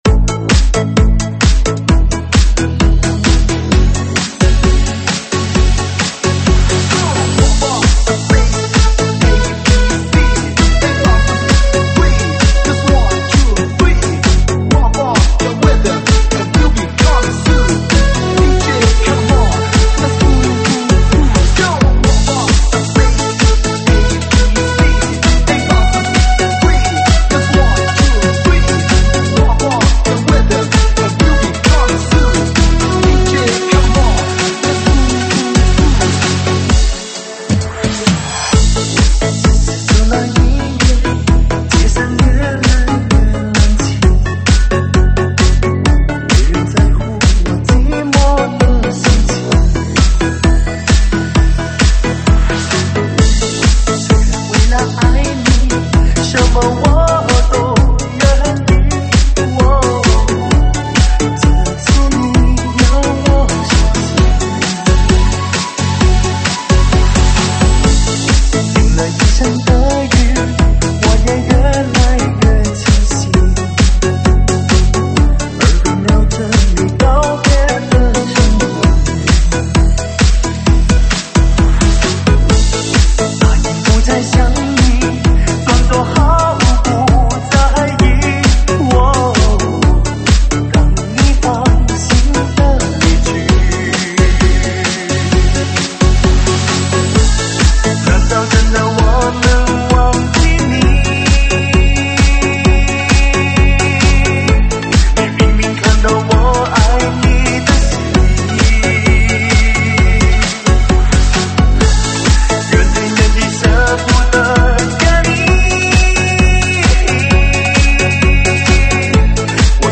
[现场串烧]
舞曲类别：现场串烧